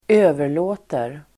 Uttal: [²'ö:ver_lå:ter]